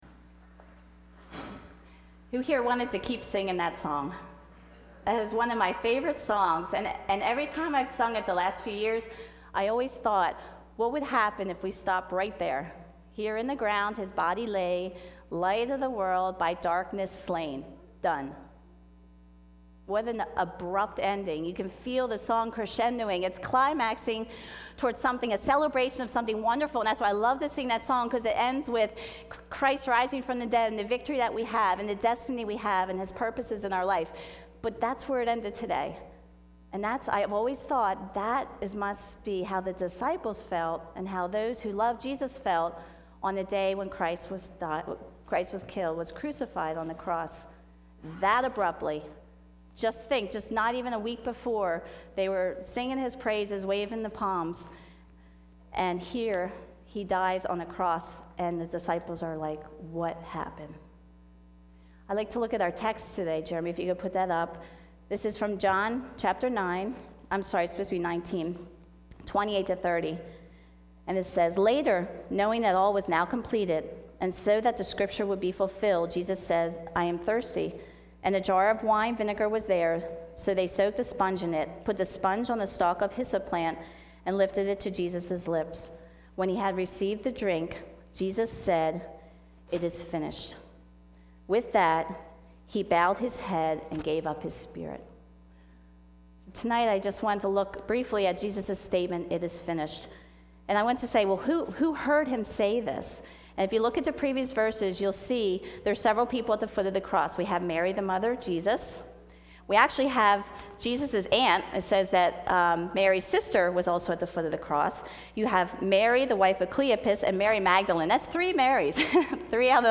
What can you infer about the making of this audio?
Occasion: Good Friday